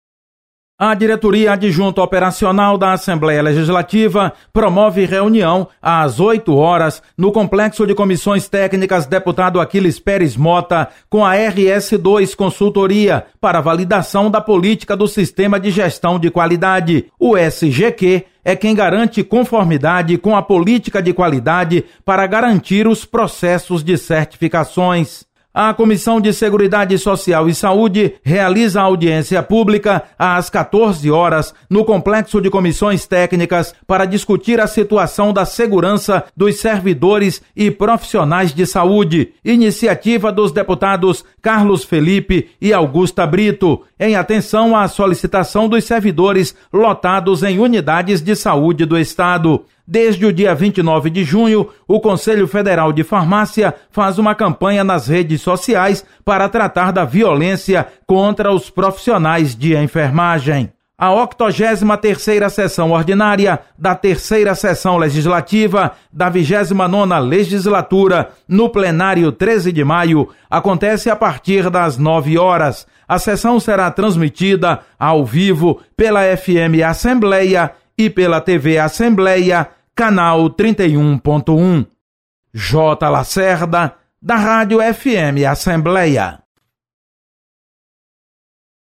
Acompanhe as atividades desta quarta-feira da Assembleia Legislativa. Repórter